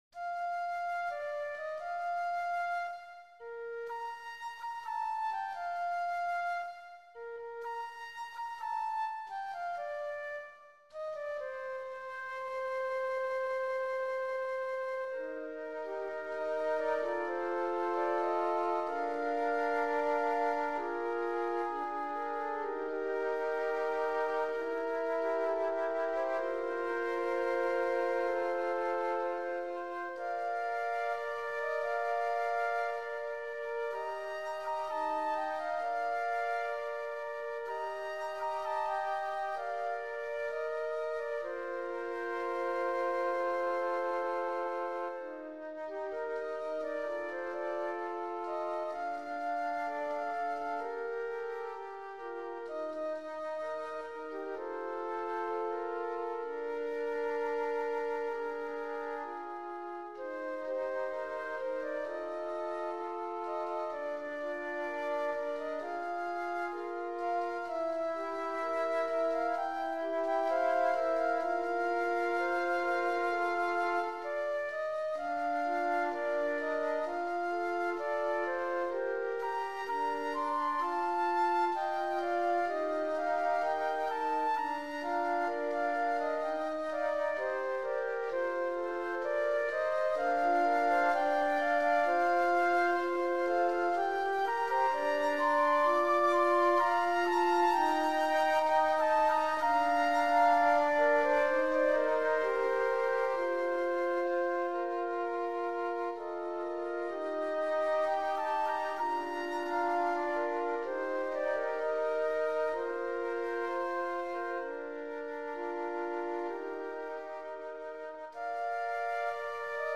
パート編成 尺八三重奏
尺八三重奏.mp3